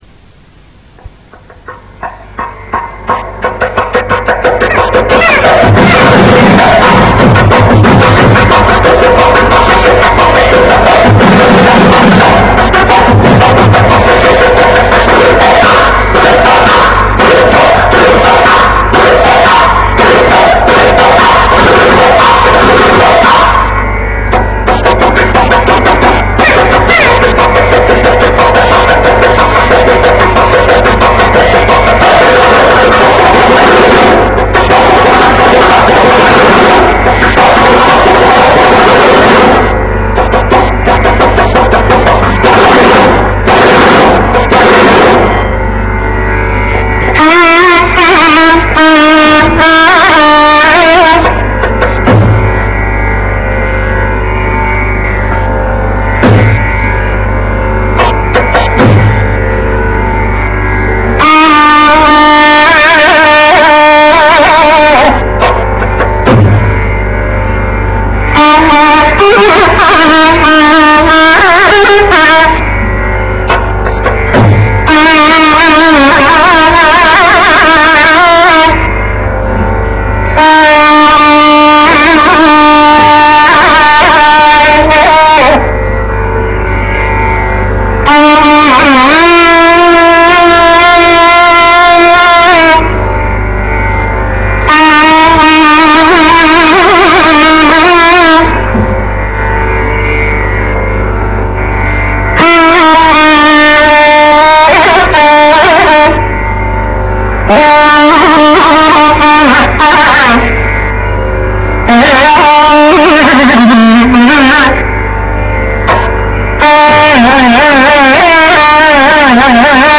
தோடிப்பண் ஆலாபனை....